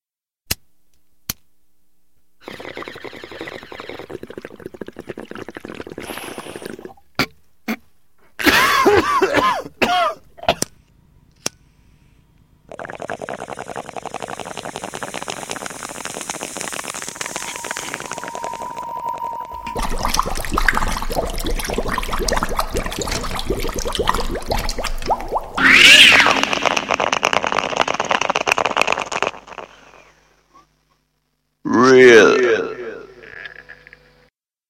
Here is my alarm clock ringer:
bongtone.mp3